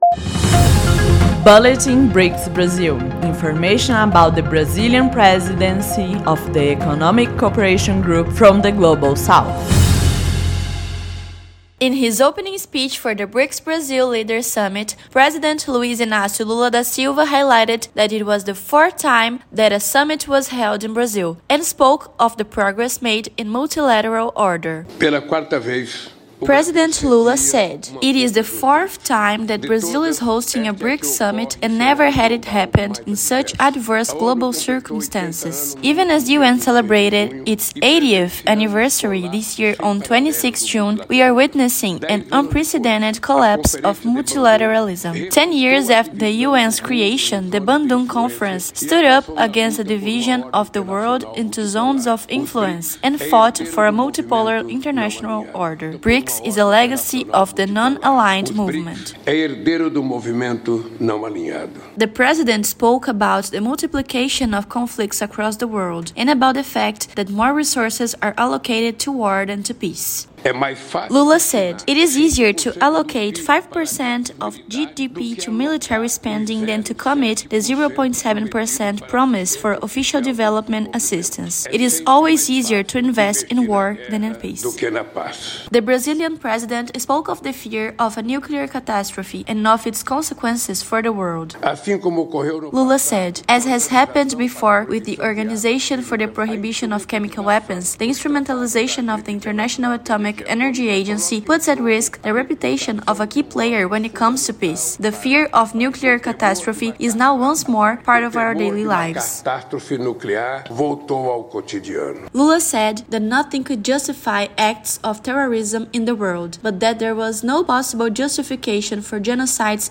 During the BRICS Summit, Lula advocates for ways to reach a new global order: reforming the UN, putting an end to wars, and prioritising development. Listen to the report to learn more.